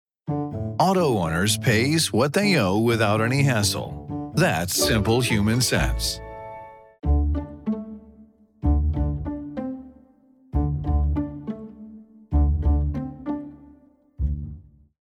Agent Insurance Radio Ads